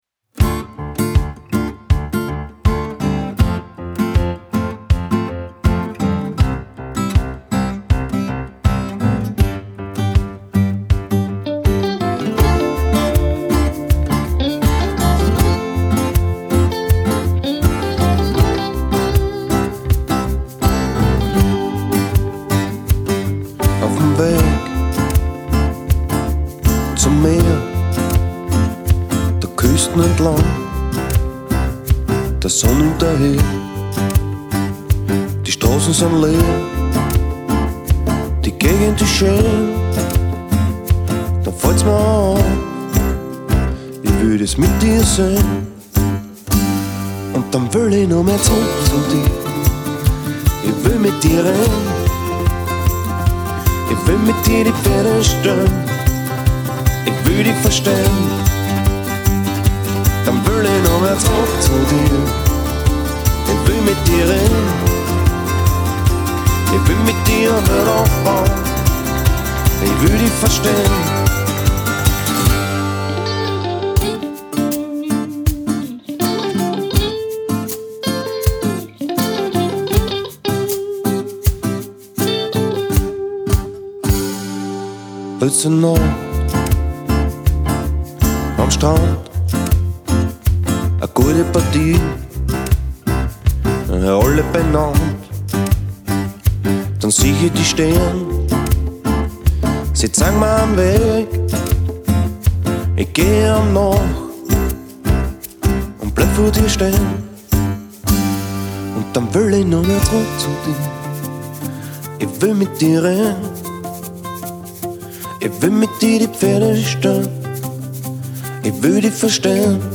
Produktion eines Uptempo-Austropop-Songs.